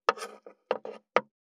573肉切りナイフ,まな板の上,包丁,
効果音